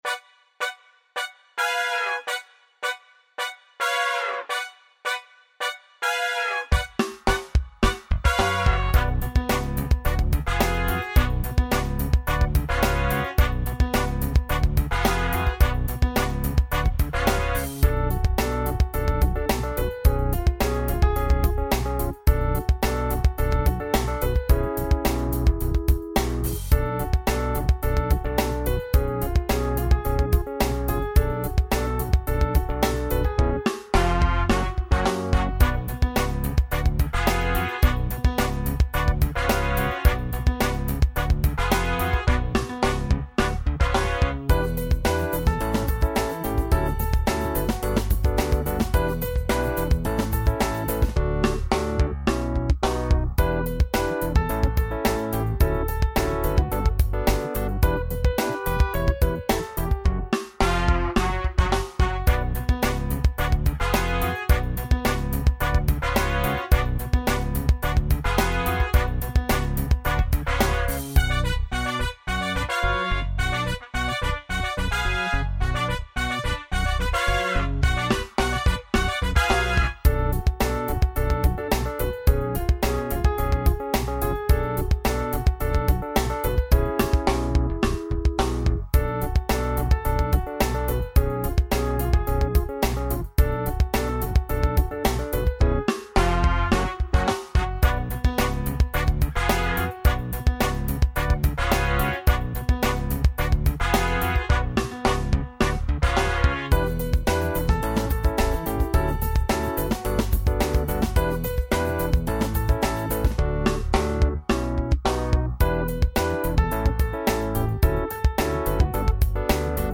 Downtown Funk - Backing Track